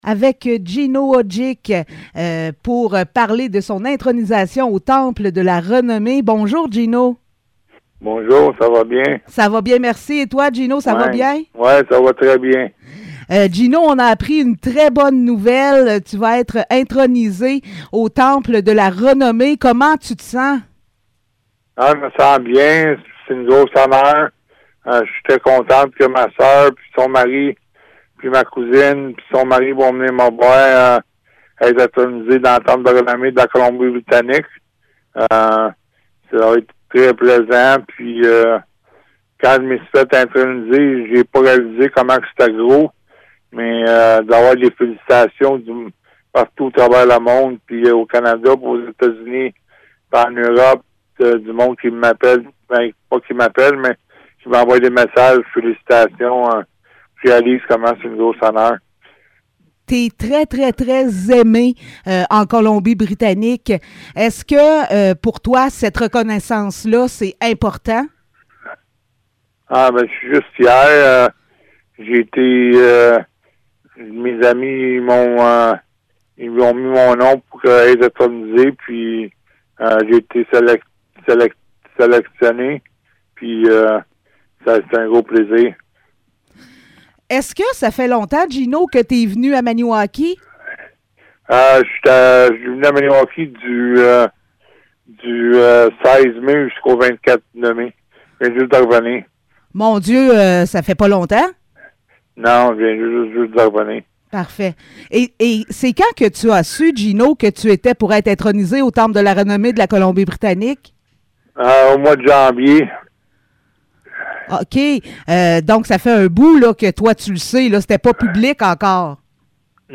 Entrevue avec Gino Odjick
Entrevues